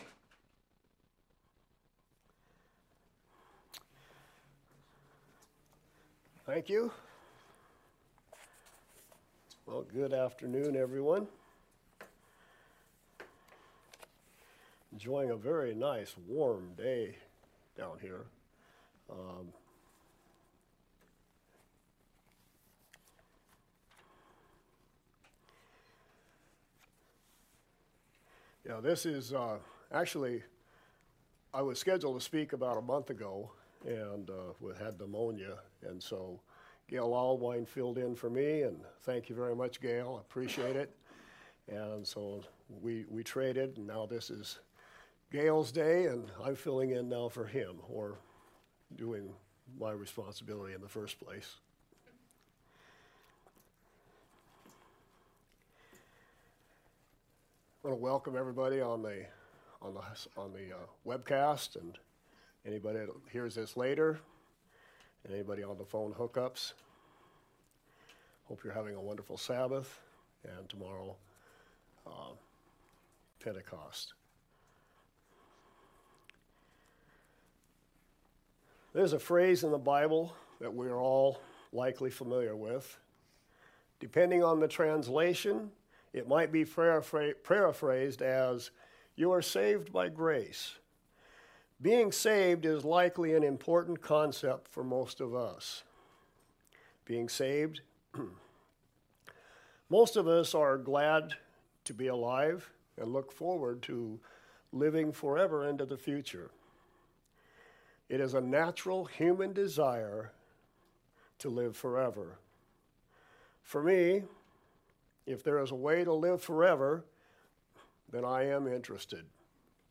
New Sermon | PacificCoG